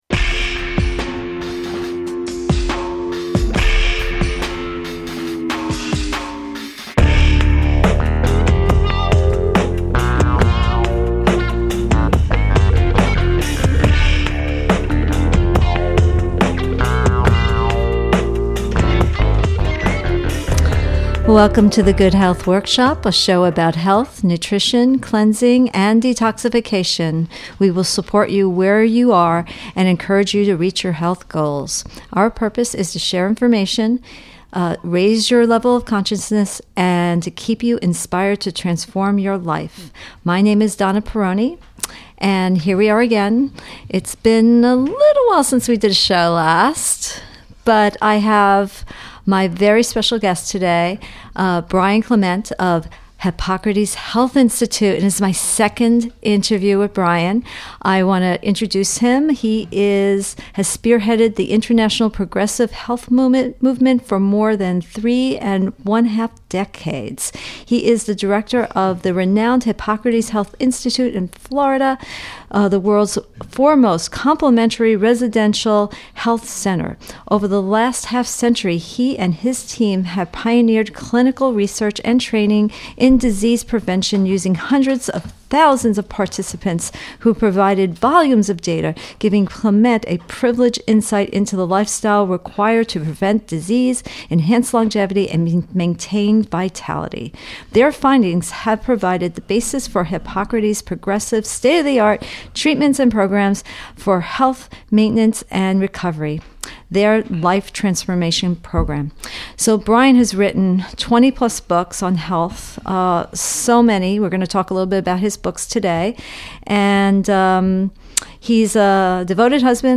This is a must listen to interview that will have you thinking twice about your lifestyle.